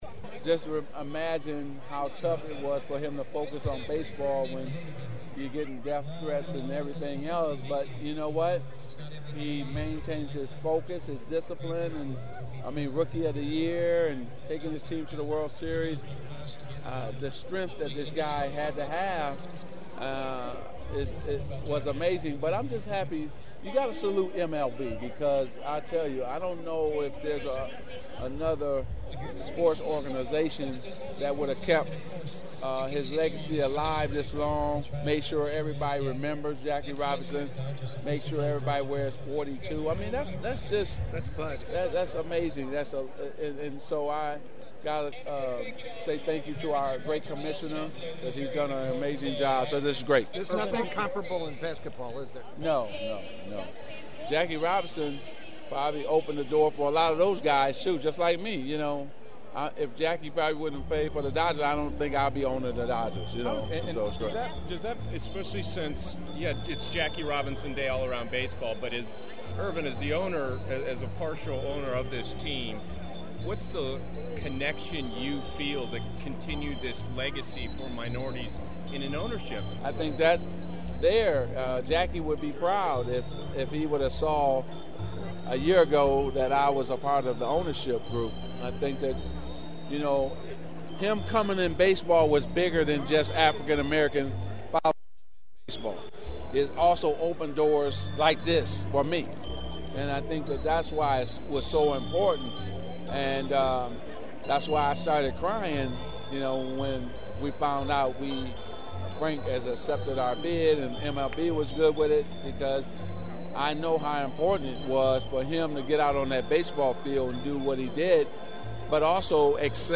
Dodger minority owner and front office guru Earvin Johnson also stopped to say a few words before taking part in the pregame ceremony and reiterated what he told us the day he took over with the club…how Jackie Robinson opened the doors to all future generations including himself!…